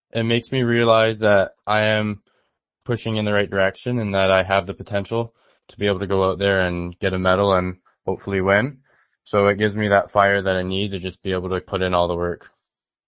In an interview with Quinte News, he says it is a career highlight.